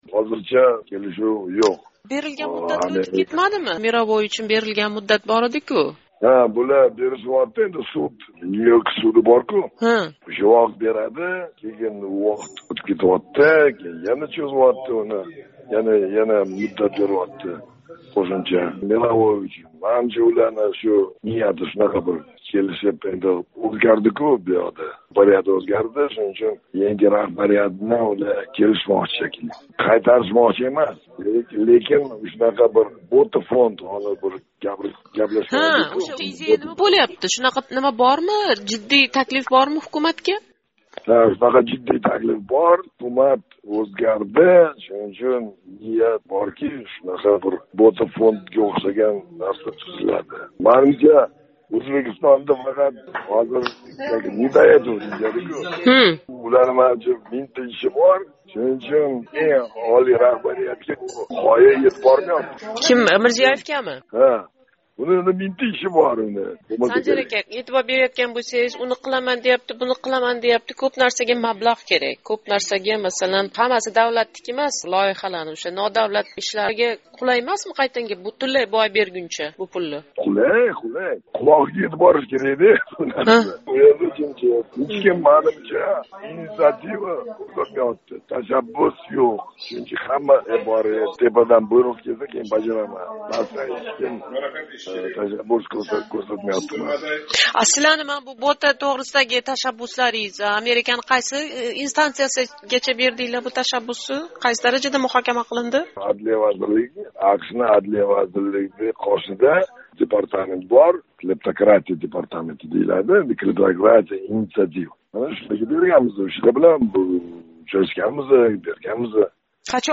суҳбат